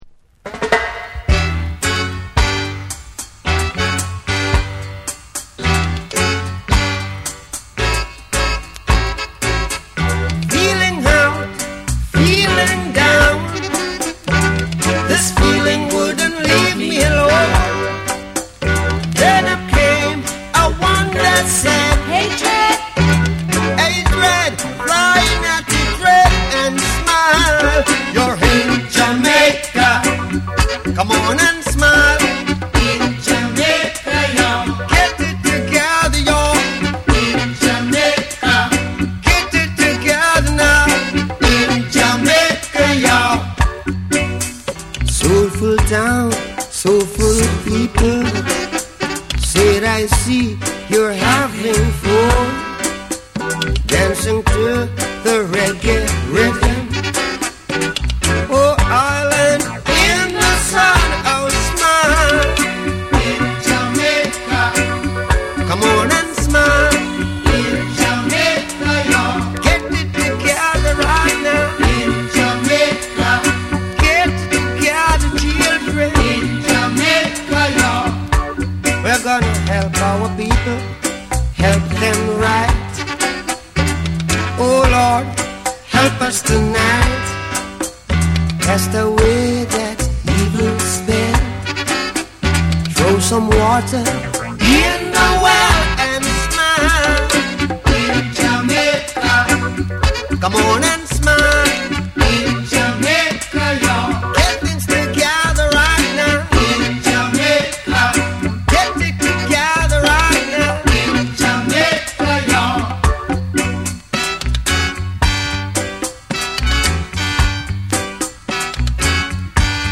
※ジャマイカ盤特有のチリノイズが入ります。